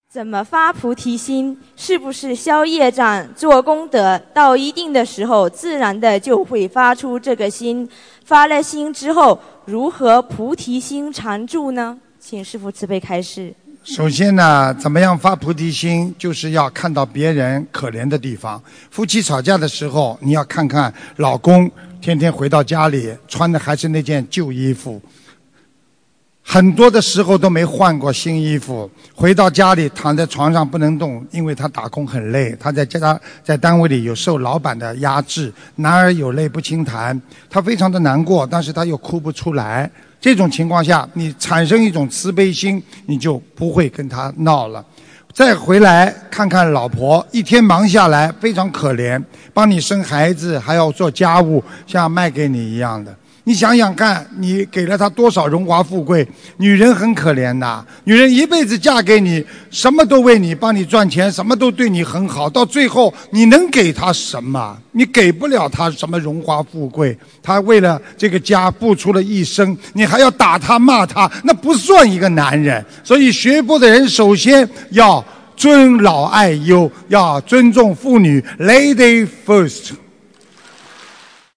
怎样发菩提心并让菩提心常住┃弟子提问 师父回答 - 2017 - 心如菩提 - Powered by Discuz!